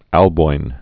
(ălboin, -bō-ĭn) Died 572.